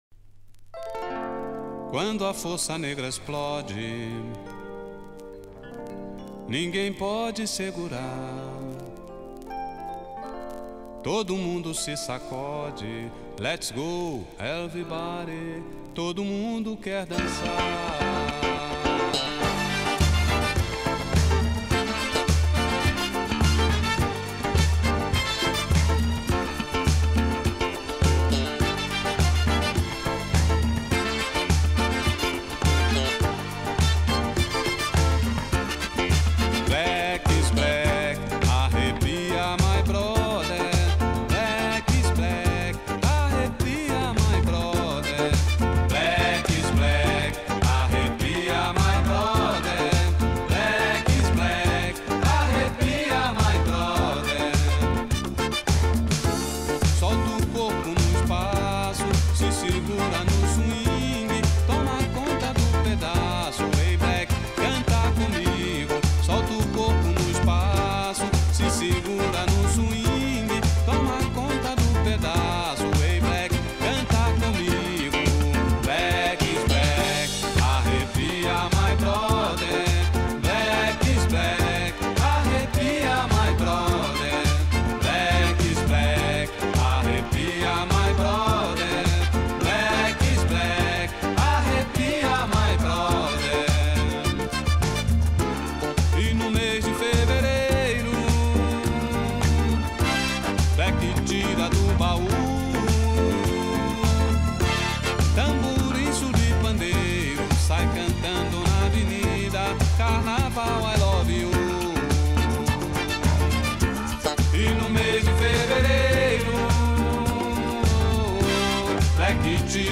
A Essência do Samba-Rock